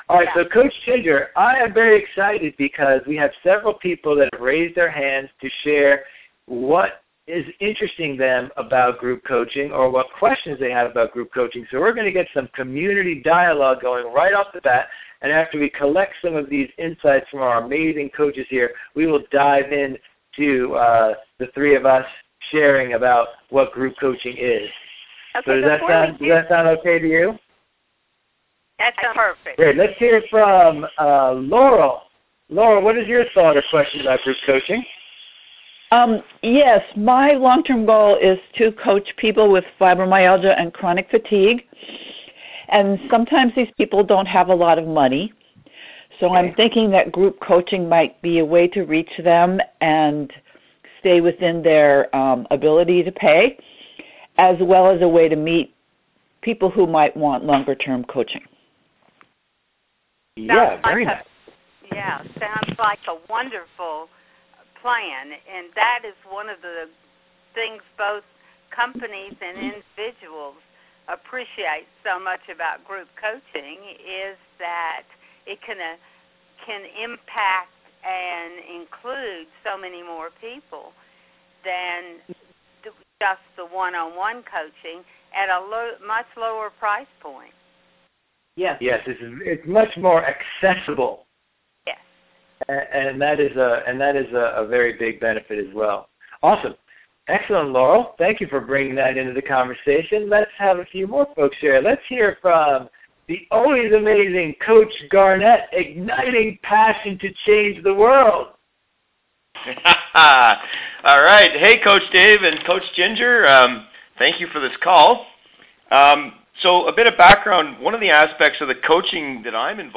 Power of Groups recorded call
Coaches asked all the big questions about how to build a successful group experience.
Power Of Group Conversation & QandA.mp3